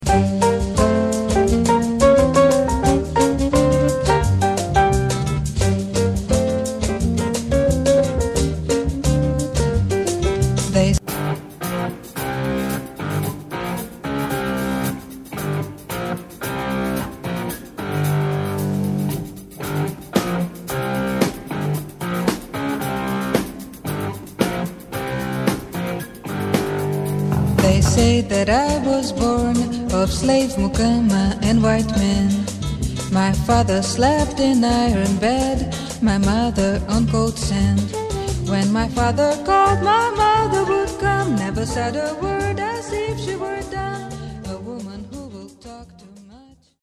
languida bossa nova
bossa nova